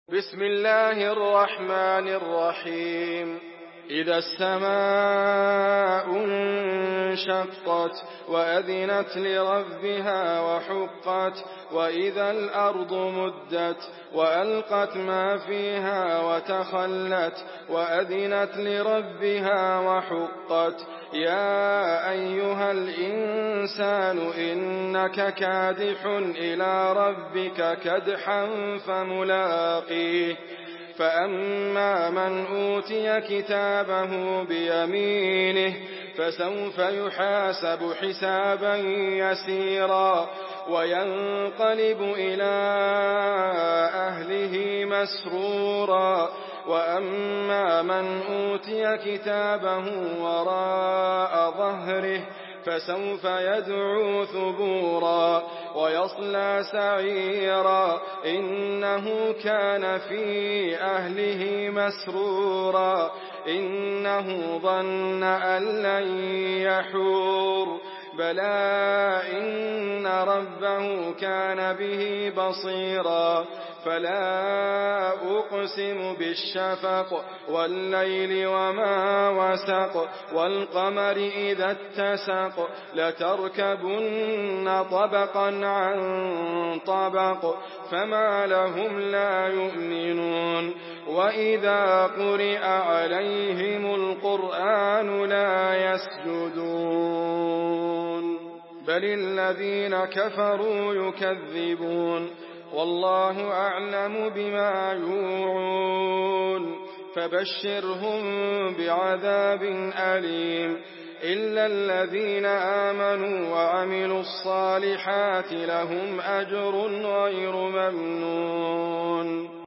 Surah Inşikak MP3 by Idriss Abkar in Hafs An Asim narration.
Murattal Hafs An Asim